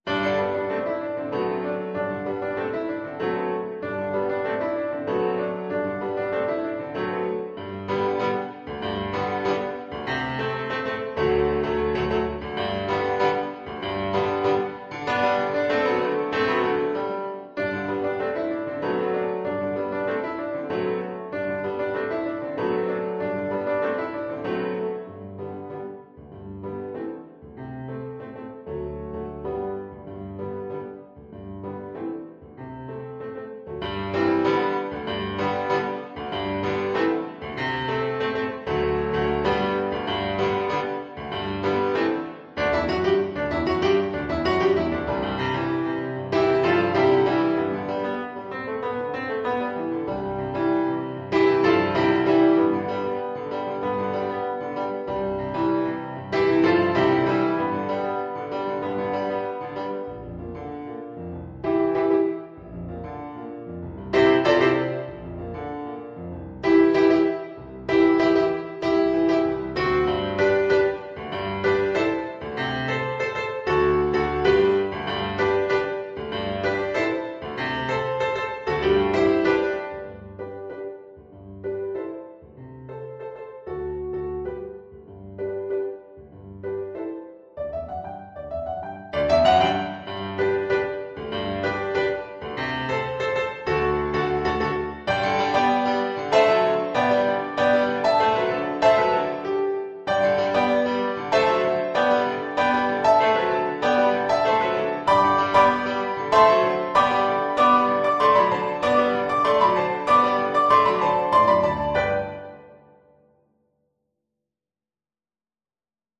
4/4 (View more 4/4 Music)
Viola  (View more Intermediate Viola Music)
Classical (View more Classical Viola Music)